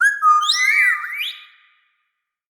File:Sfx creature seamonkey taunt 03.ogg - Subnautica Wiki